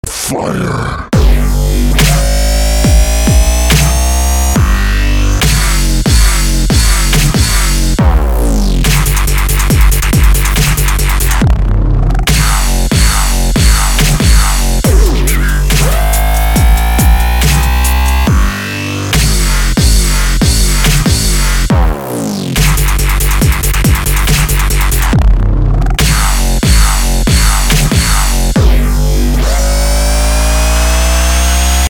• Качество: 192, Stereo
Electronic
club
Dubstep